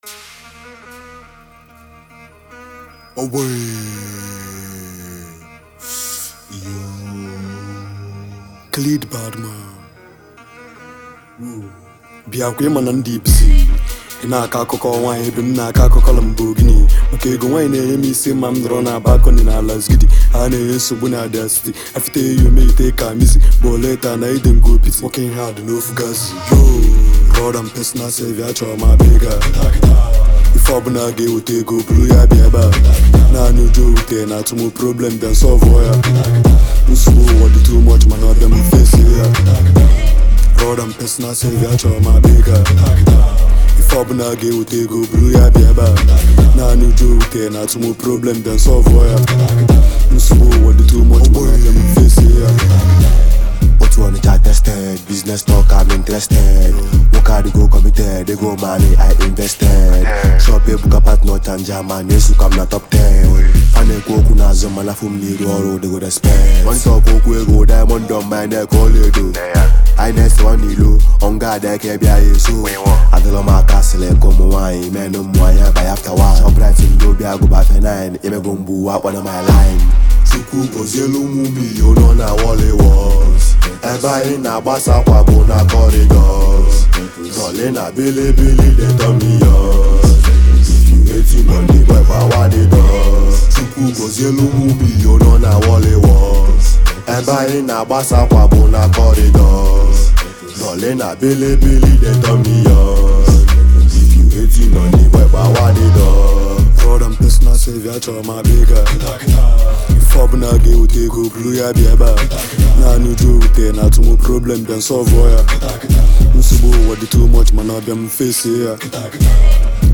trap hit track
Do you enjoy rap music?